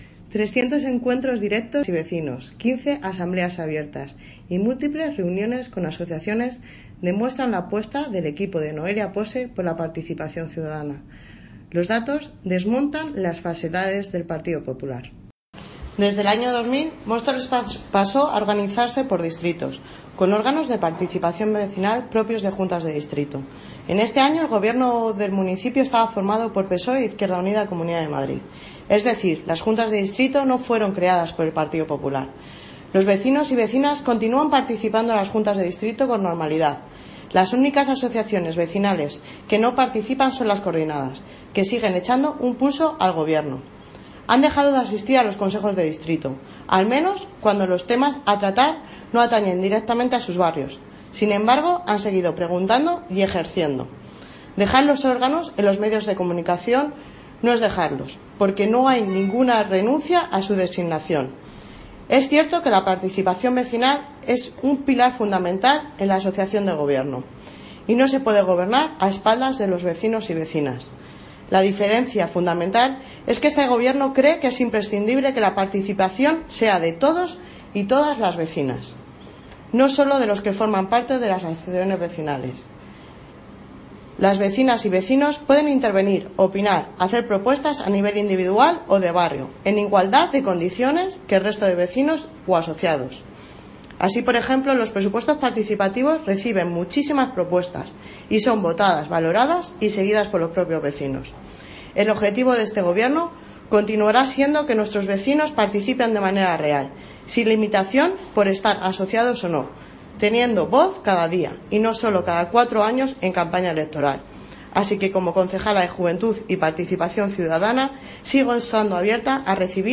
Audio - Aránzazu Fernández (Concejala de Juventud y Participación Ciudadana) Sobre Participacion